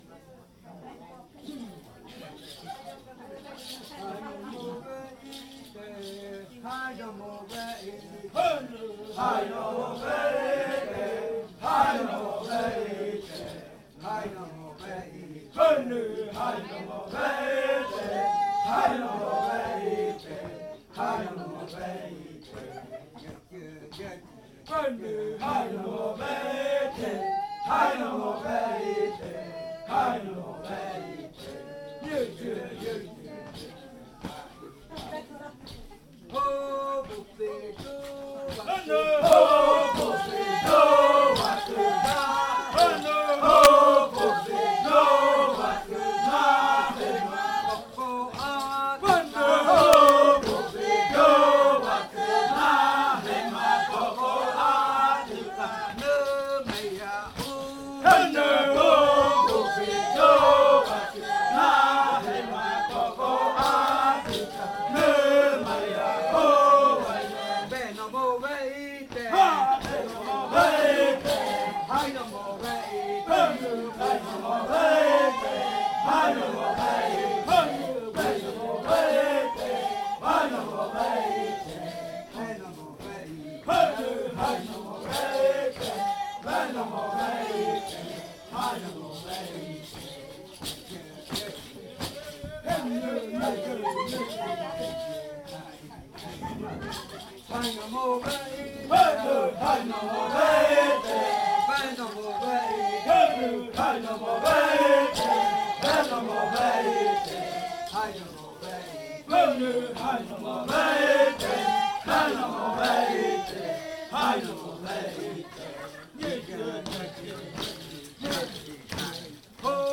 Canto de saltar de la variante jaiokɨ
Leticia, Amazonas
con el grupo de cantores bailando en la Casa Hija Eetane.
with the group of singers dancing at Casa Hija Eetane.